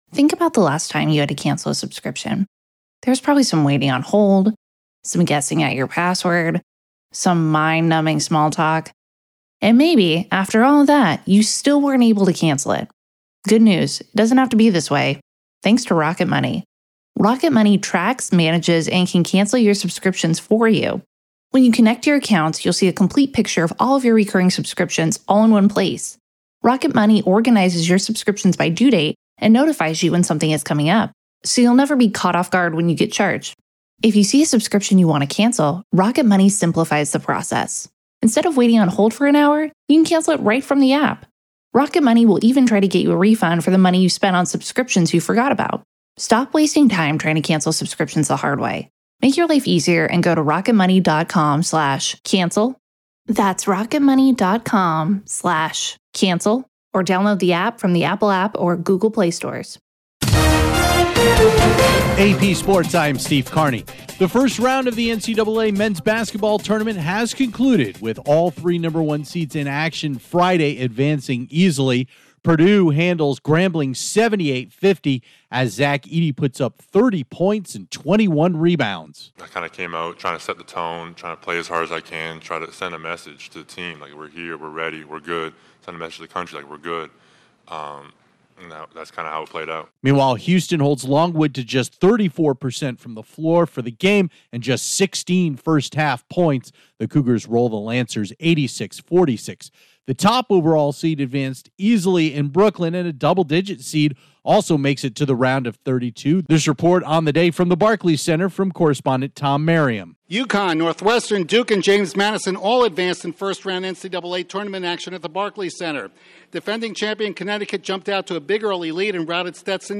Correspondent